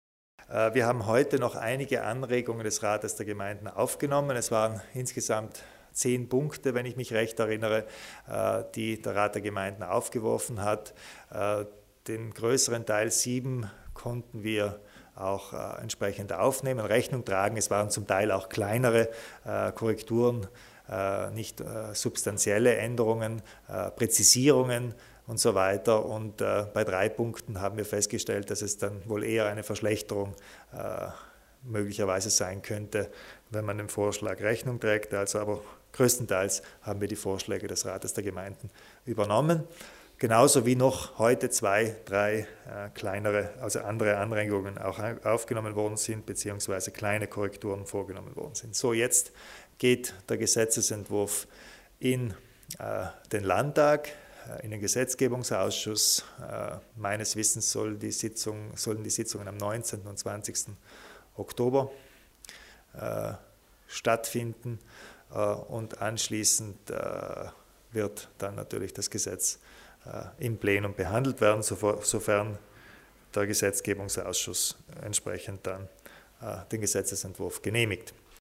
Landeshauptmann Kompatscher erläutert das neue Vergabegesetz